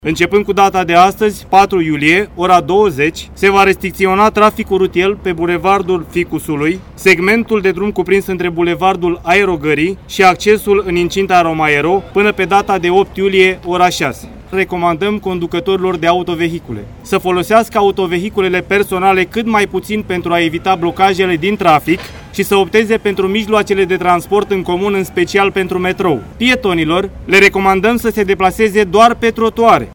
Agentul de poliţie